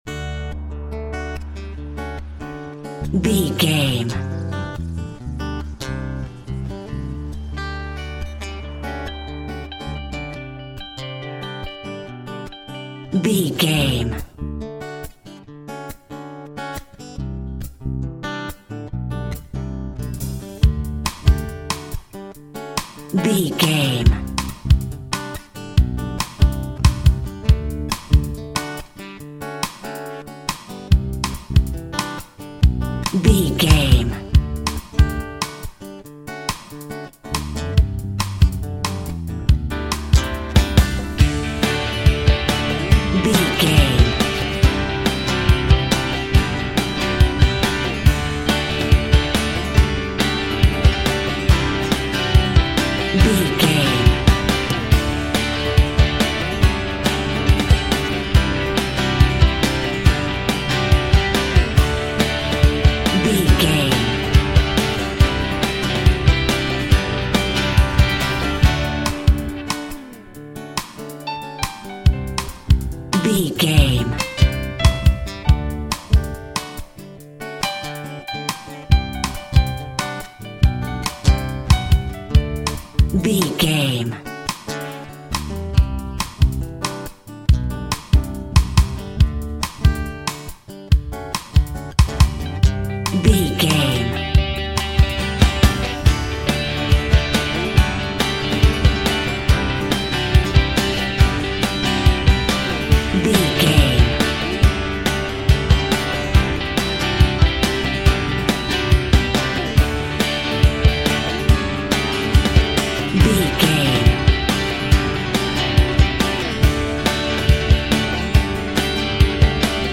Uplifting
Ionian/Major
D
DOES THIS CLIP CONTAINS LYRICS OR HUMAN VOICE?
Instrumental rock
drums
bass guitar
electric guitar
piano
hammond organ